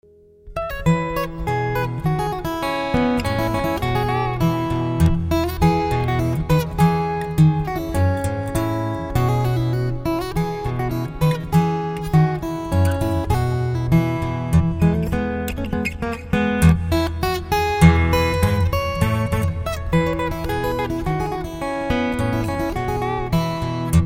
Artistic Solo Guitarist